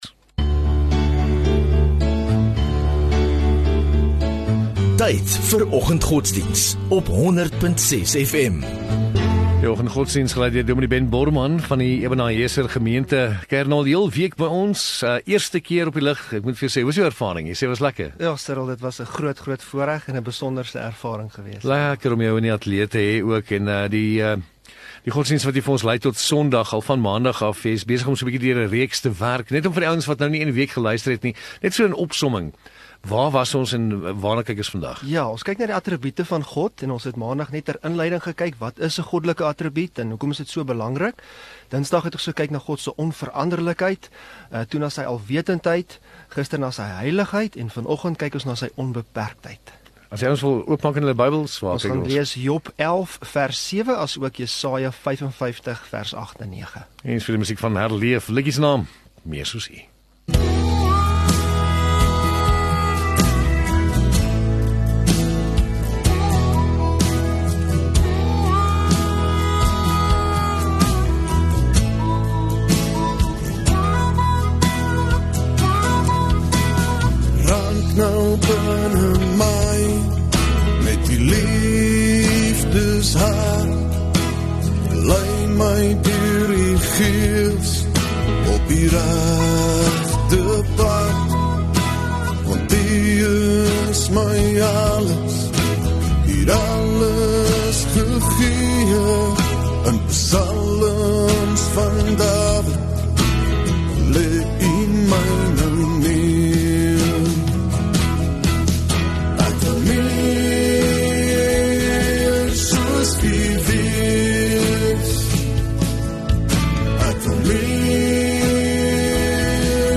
24 May Vrydag Oggenddiens